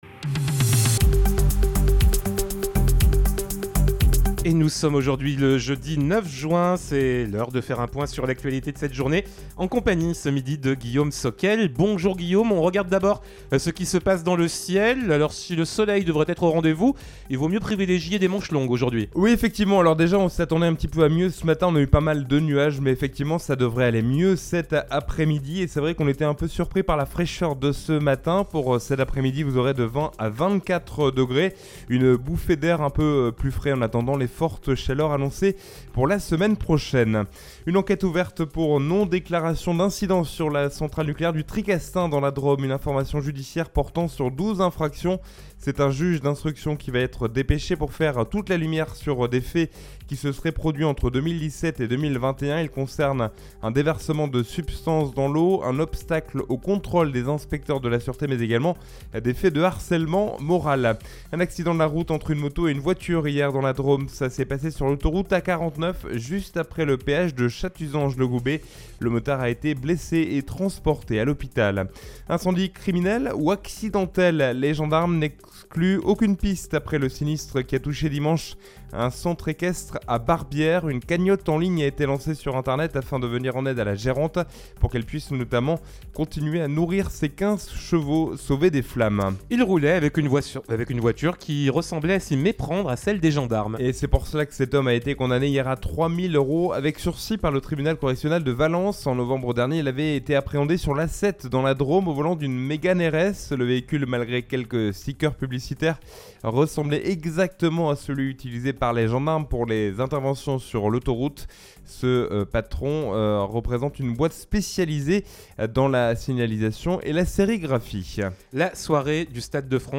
in Journal du Jour - Flash
Jeudi 9 juin 2022 : Le journal de 12h